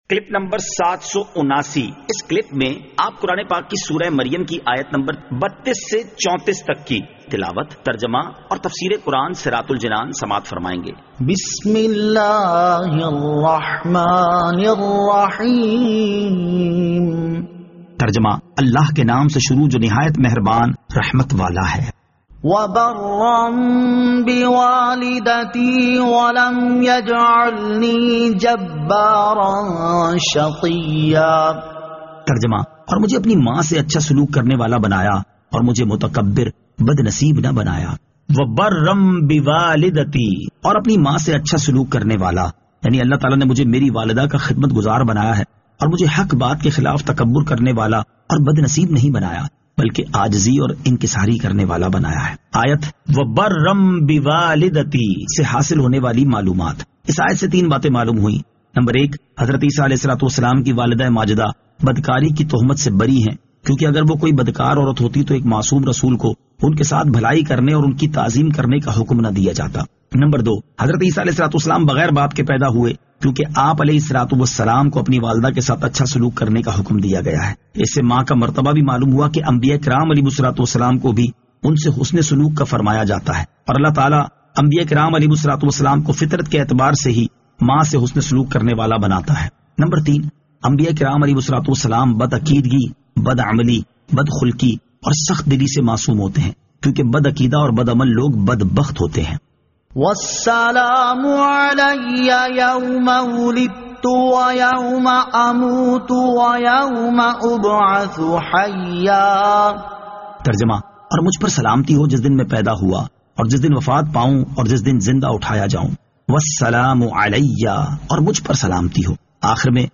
Surah Maryam Ayat 32 To 34 Tilawat , Tarjama , Tafseer